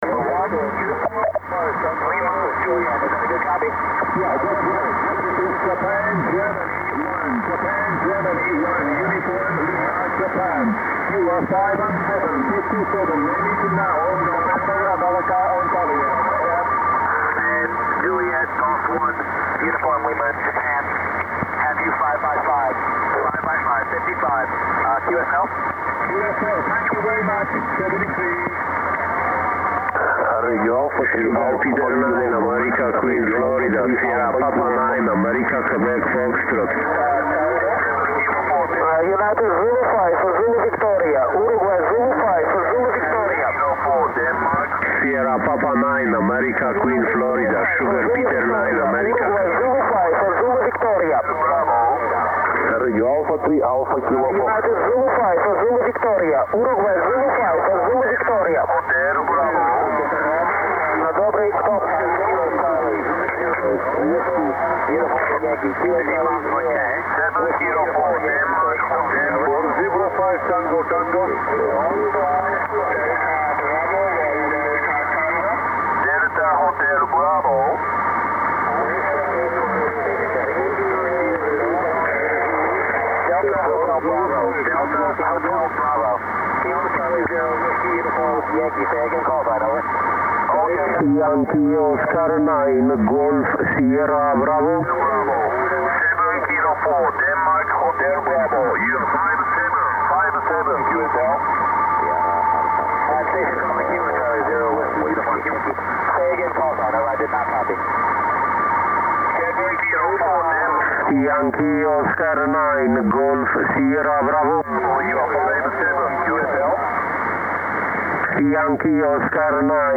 Sound & Video File from Asia
ssb
Areonautical Mobile
Location South Asia